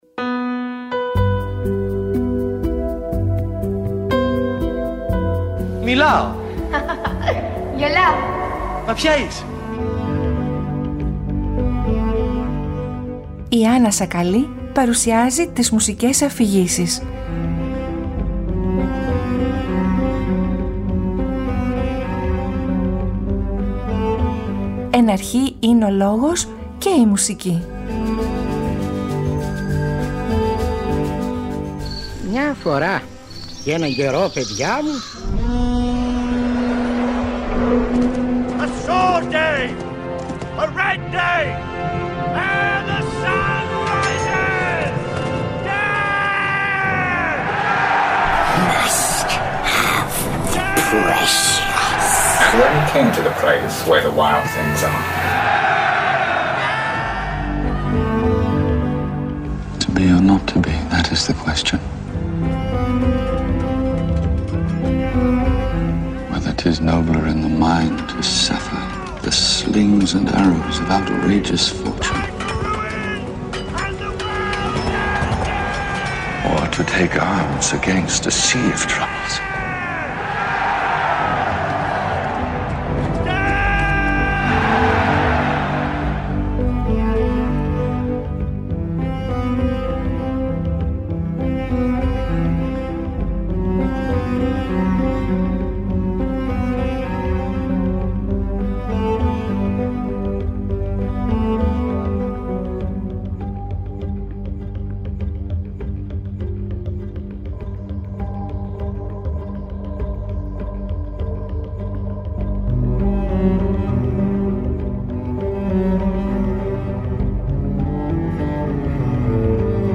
Ακούστε – για πρώτη φορά μεταφρασμένο – το διήγημα αυτό.